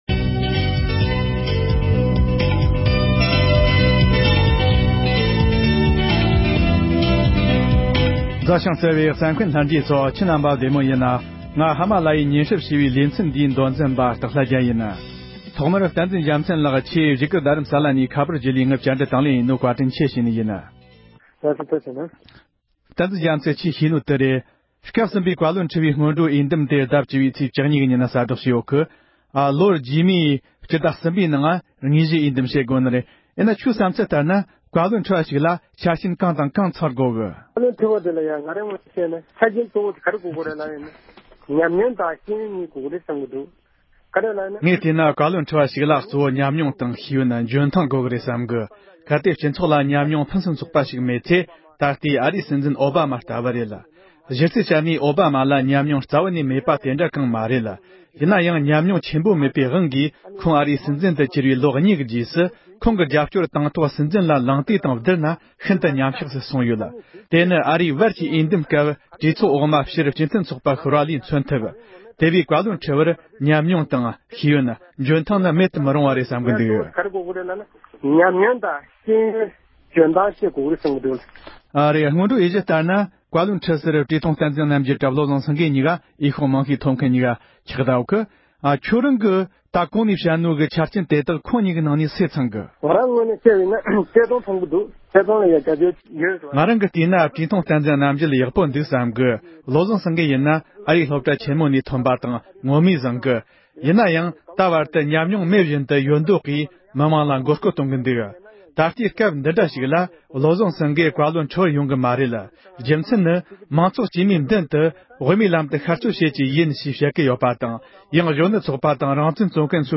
བཀའ་བློན་ཁྲི་པའི་གཞི་རྩའི་ཆ་རྐྱེན་གང་འདྲ་ཞིག་དགོས་ཀྱི་ཡོད་མེད་ཐད་བགྲོ་གླེང༌།
སྒྲ་ལྡན་གསར་འགྱུར།